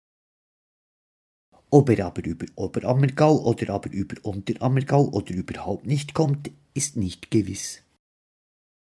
Zungenbrecher